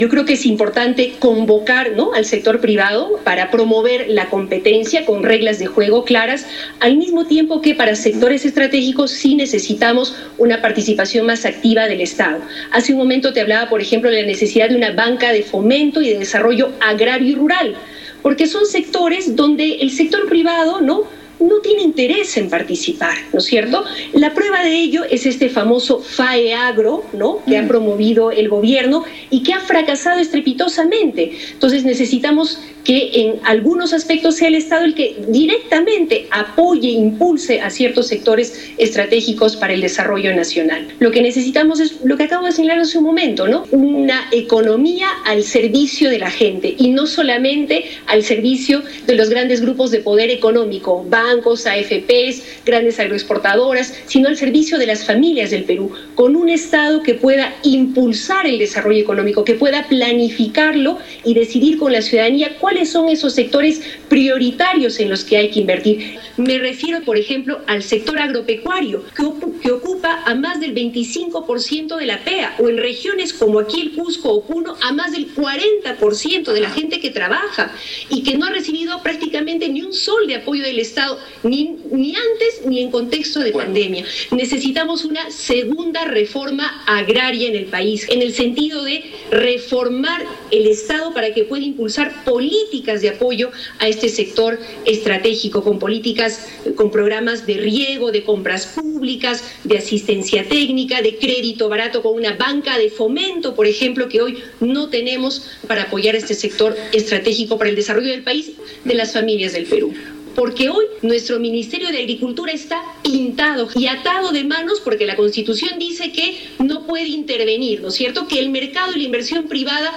Tal como lo ha sostenido en una reciente entrevista, Verónica Mendoza, ha remarcado la necesidad de que el Estado impulse programas de apoyo a la agricultura familiar, como un programa nacional de riego, programas de compras nacionales de alimentos, así como la implementación de una banca de fomento estatal para apoyar a este sector productivo.
Escuchemos las propuestas que plantea Verónica Mendoza sobre la agricultura familiar: